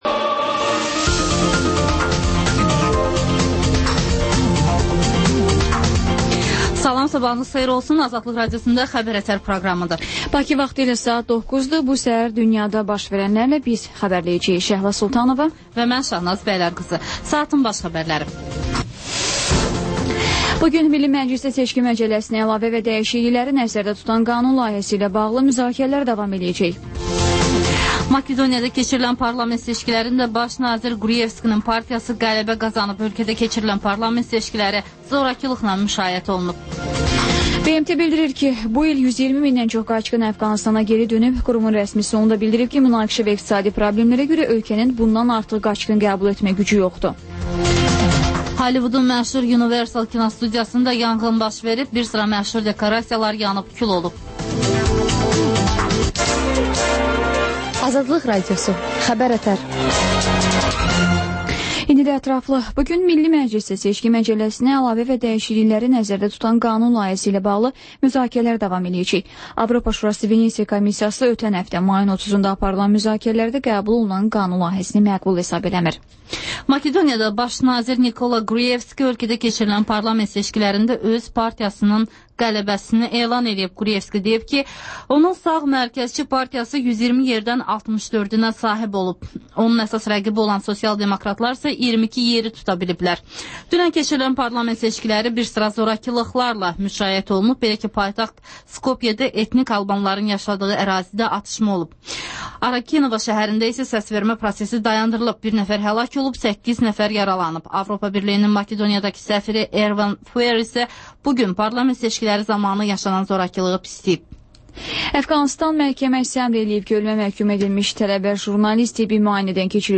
Xəbər-ətər: xəbərlər, müsahibələr, sonra TANINMIŞLAR rubrikası: Ölkənin tanınmış simaları ilə söhbət